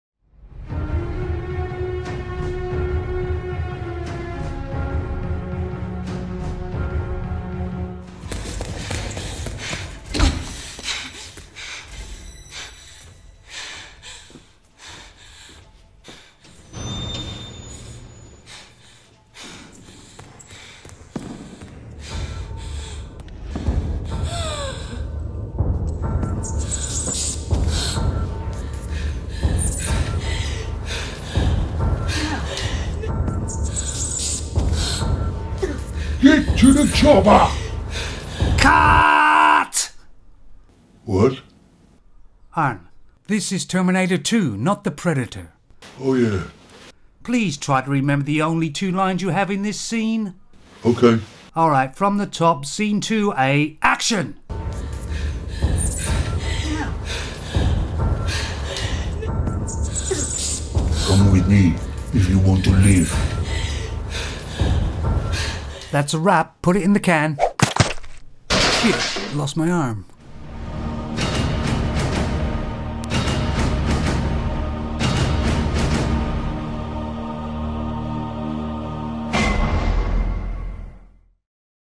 That had some good musical scores...